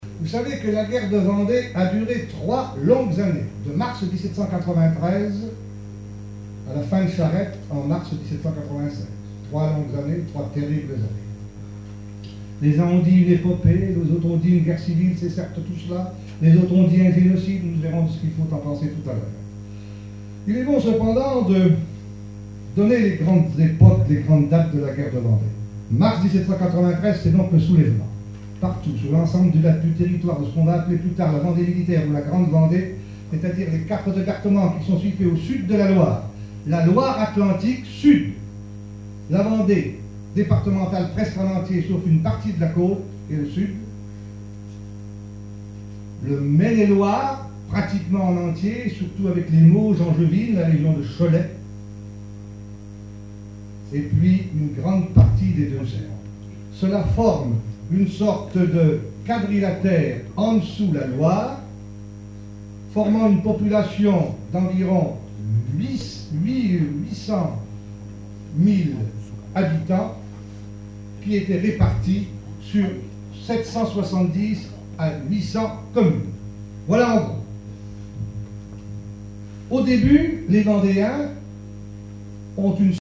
Conférence de la Société des écrivains de Vendée
Catégorie Témoignage